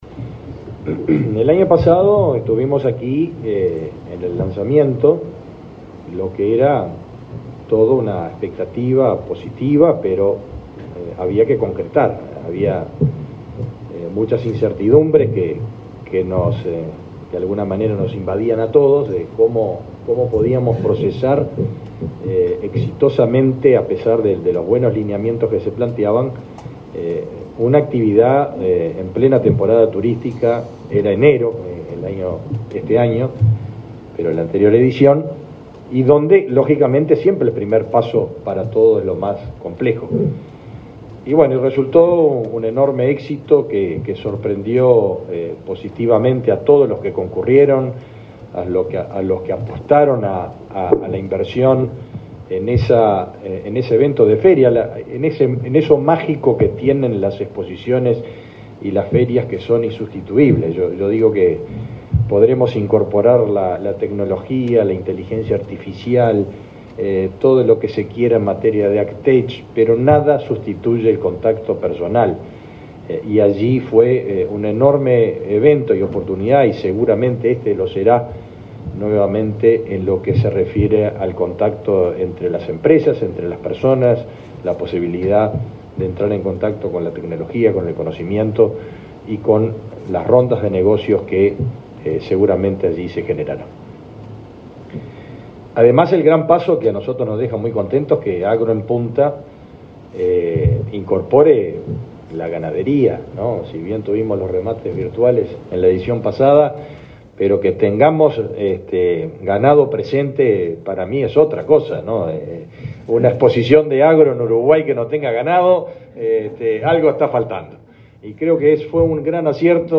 Palabras del ministro de Ganadería, Fernando Mattos
El ministro de Ganadería, Fernando Mattos, participó en el lanzamiento de Agro en Punta Expo & Business, que se realizó este miércoles 25 en la sede